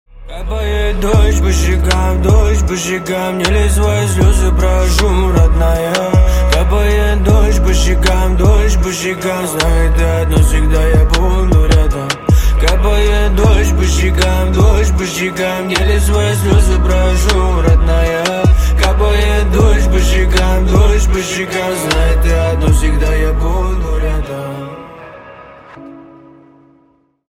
# Грустные Рингтоны
# Рэп Хип-Хоп Рингтоны